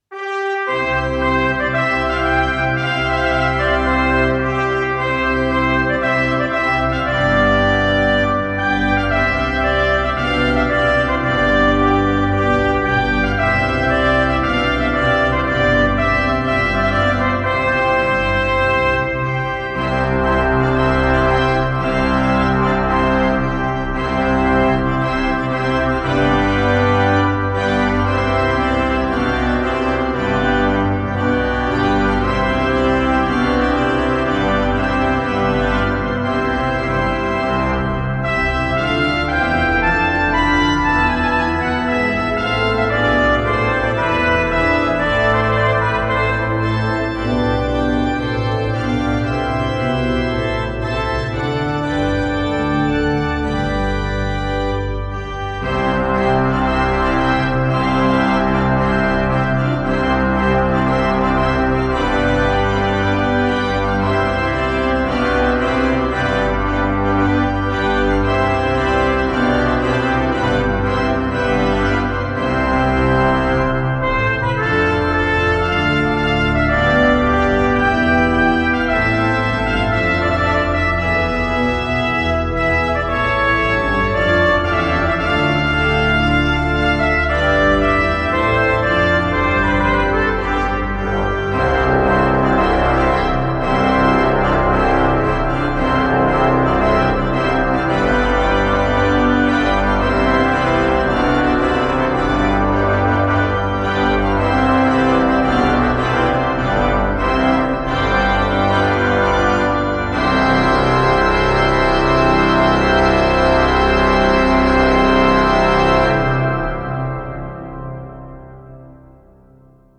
Der festliche Charakter dieses Werks zeigt sich in einer markanten Trompetenstimme (möglicherweise als Solopart gedacht), im Wechsel mit einem kraftvollen französischen plein-jeu und einem eindrucksvollen grand-jeu als Abschluss.